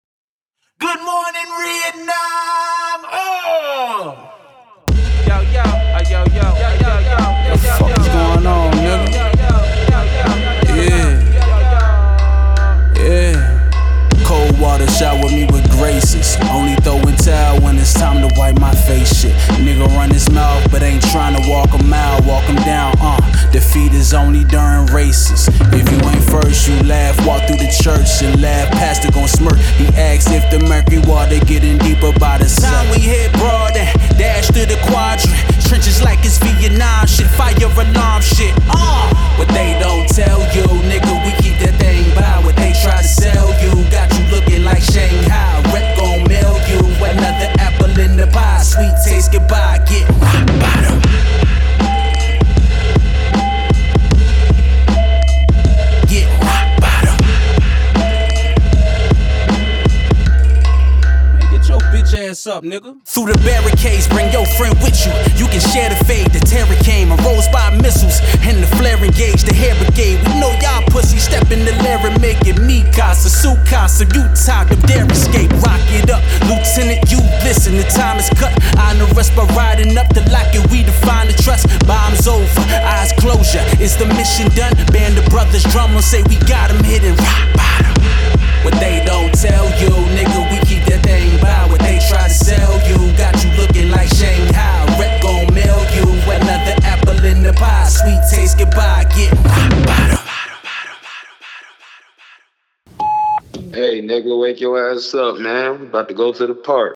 hip-hop
Gritty in tone and seething in intent